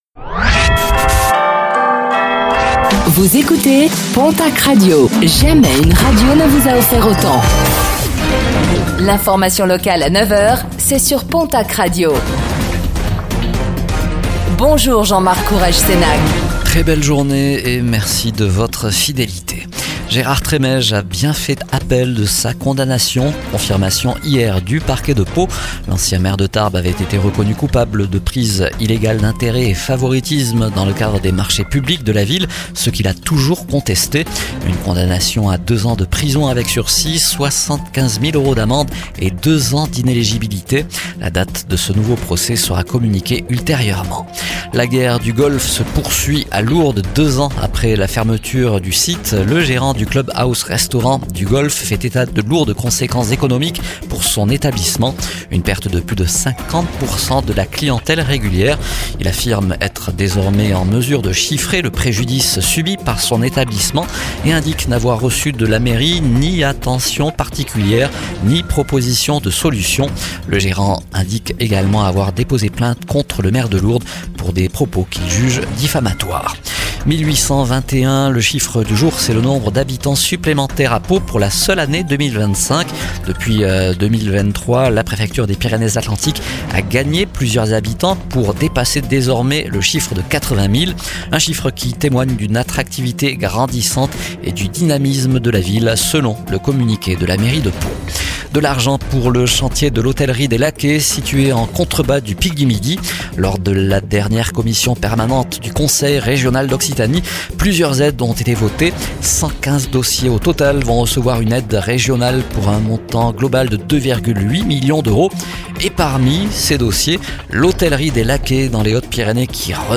Réécoutez le flash d'information locale de ce jeudi 18 décembre 2025 , présenté par